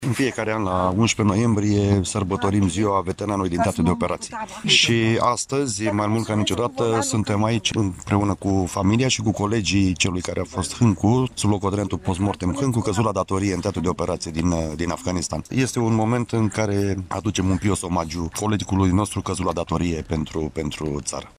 La Iași, Ziua Veteranilor de război a fost marcată la Monumentul Eroilor din Cimitirul Eternitatea.